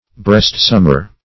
Brestsummer \Brest"sum`mer\, n.